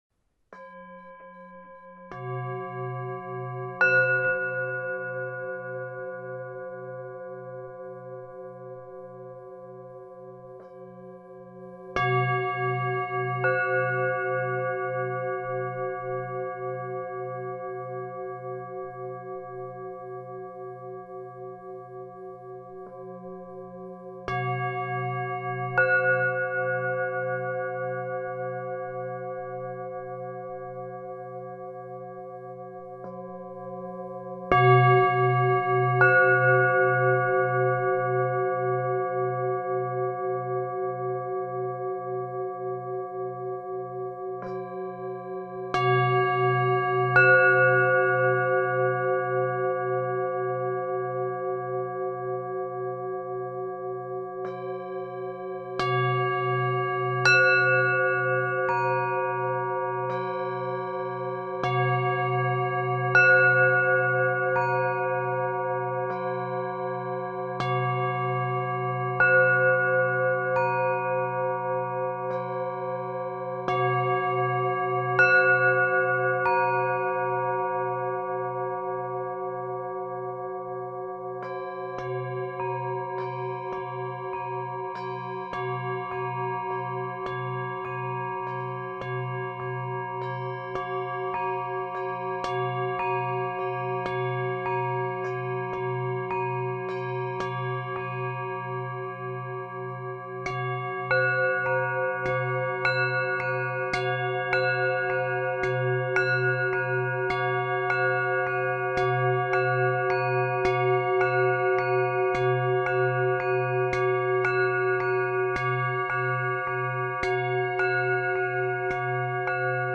Спонтанная Музыка для очищения души